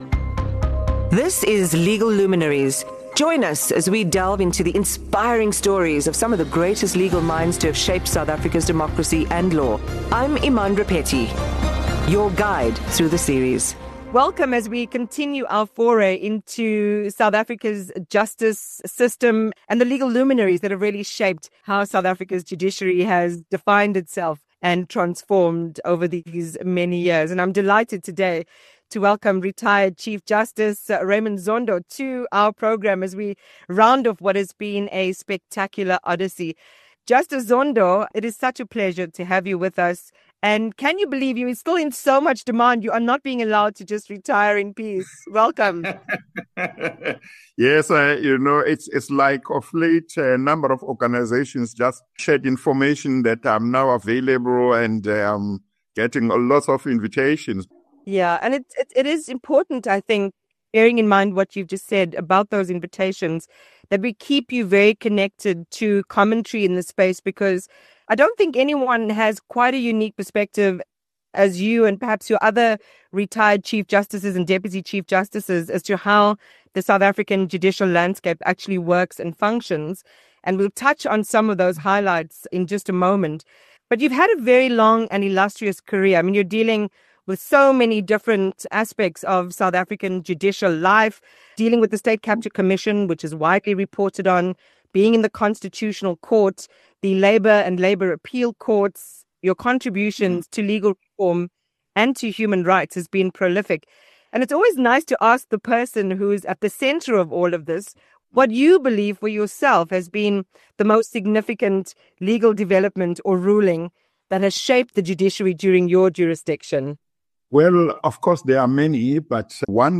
Looking ahead, he shares his thoughts on the potential role of AI in the courtroom and the future of justice in a rapidly evolving world. This episode provides a rare opportunity to hear firsthand from one of South Africa’s most influential legal minds on the complexities, triumphs, and responsibilities of upholding the rule of law in a modern democracy.